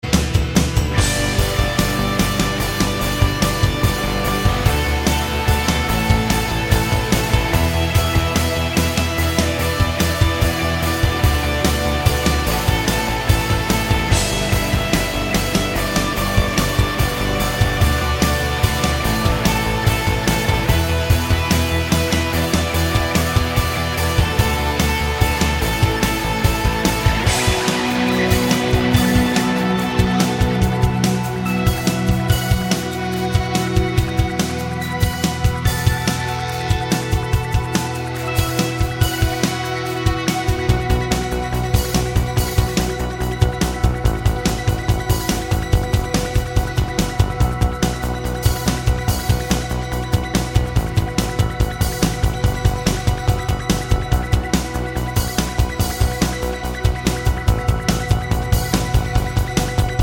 no Backing Vocals Rock 4:44 Buy £1.50